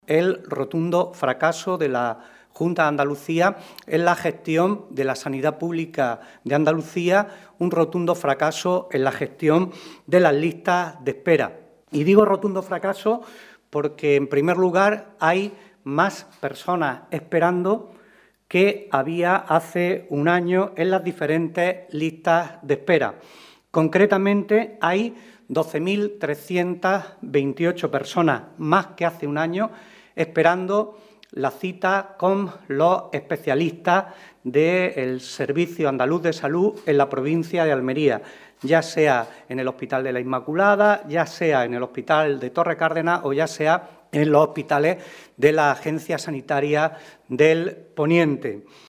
José Luis Sánchez Teruel en rueda de prensa